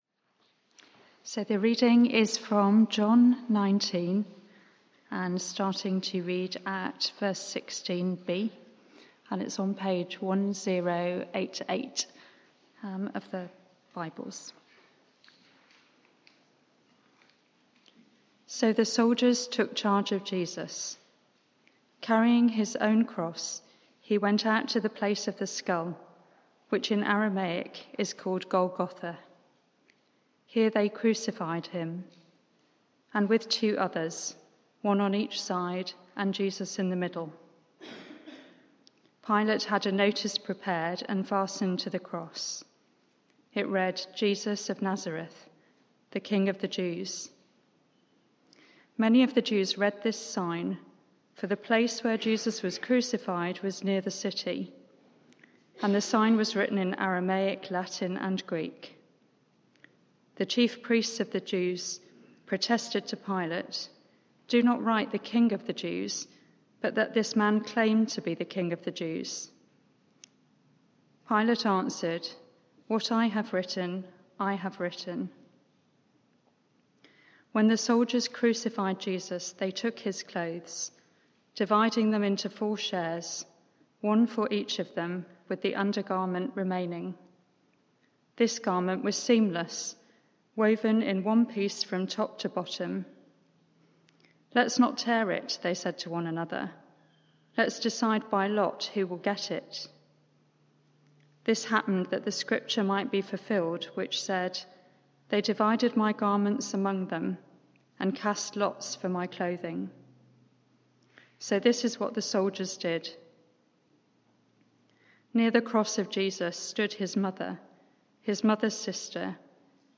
Media for 6:30pm Service on Sun 10th Apr 2022 18:30 Speaker
Theme: It is finished Sermon (audio)